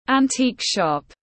Antique shop /ænˈtiːk ʃɒp/